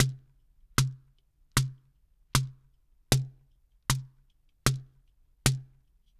AFP SHEKERE.wav